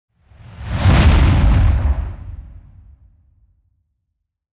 دانلود آهنگ موشک 3 از افکت صوتی حمل و نقل
جلوه های صوتی
دانلود صدای موشک 3 از ساعد نیوز با لینک مستقیم و کیفیت بالا